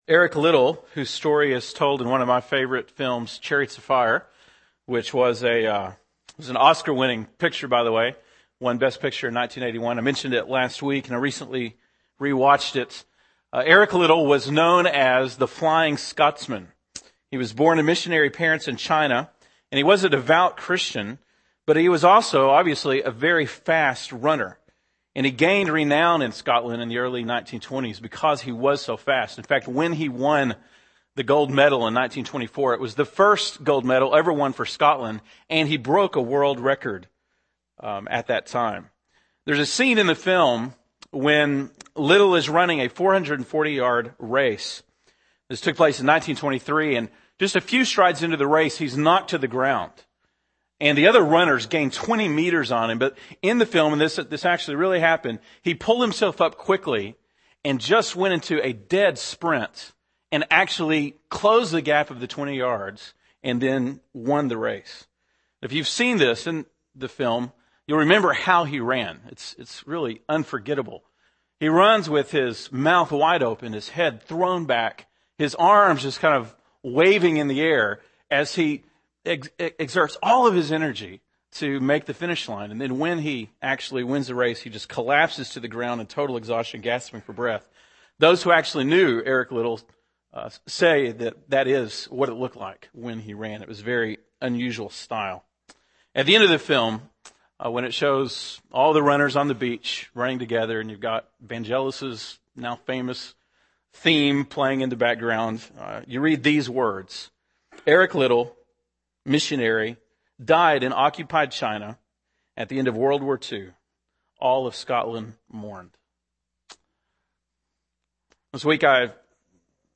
November 1, 2009 (Sunday Morning)